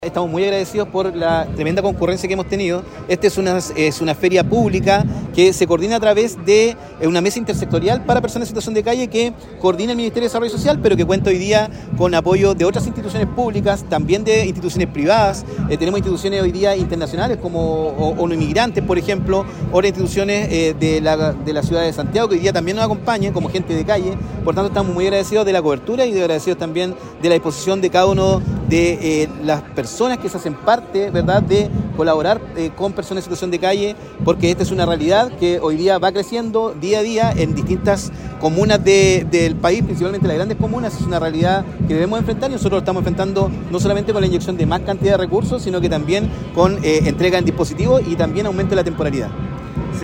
El seremi de Desarrollo Social y Familia, Hedson Díaz, manifestó su agradecimiento a quienes participaron de la feria.